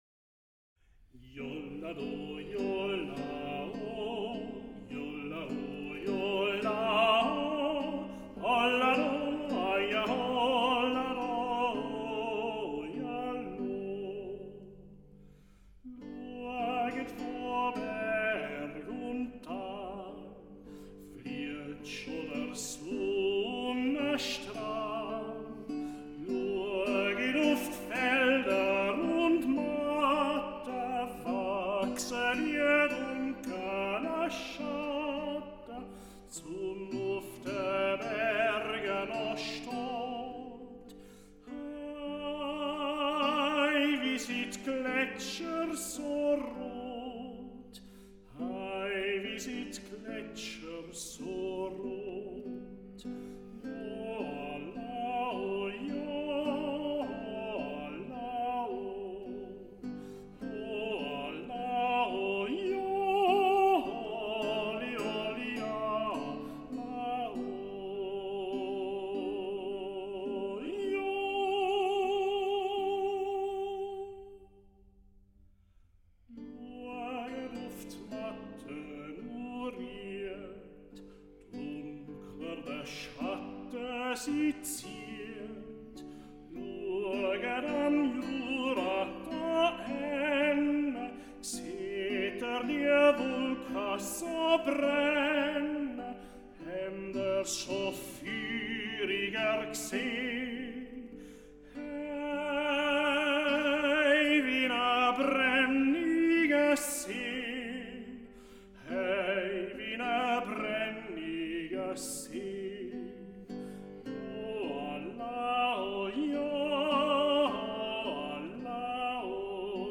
Tenor
guitar.